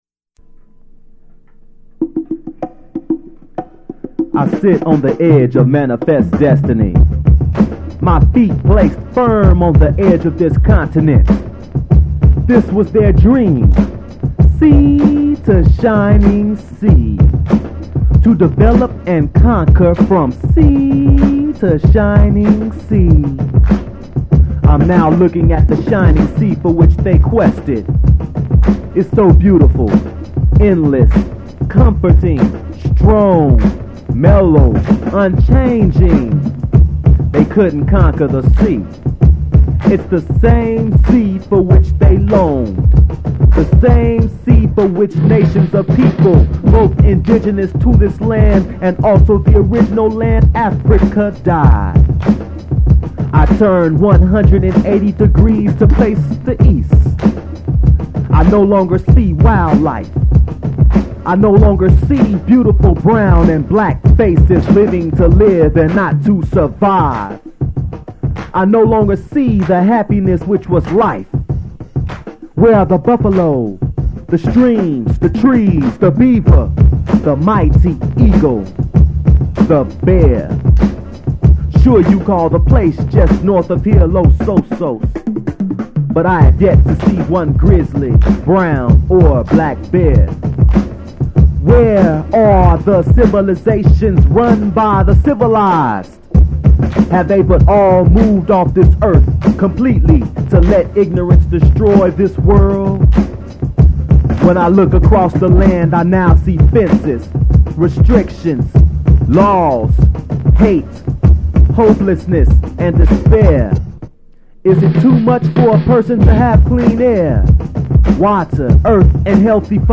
Form of original Audiocassette